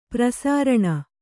♪ prasāraṇa